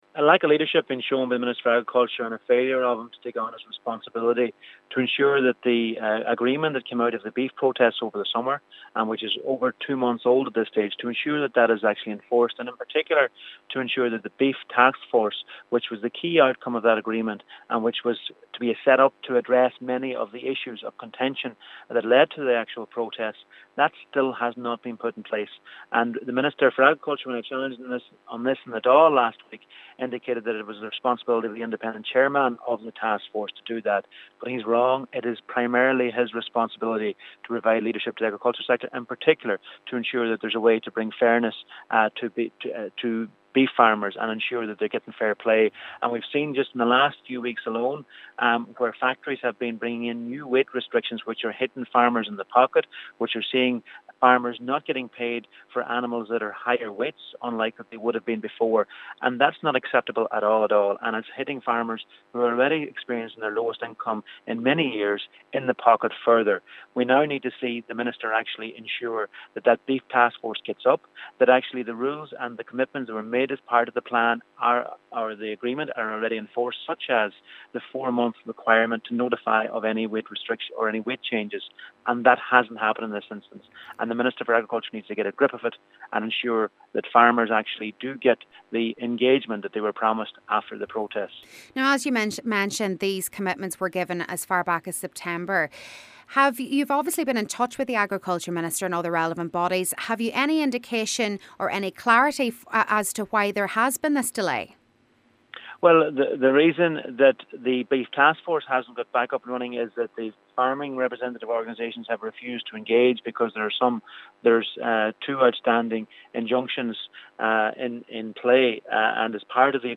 Deputy and Fianna Fail Agriculture Spokesperson Charlie McConalogue says farmers feel abandoned with many reforms agreed in September still in limbo.